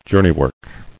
[jour·ney·work]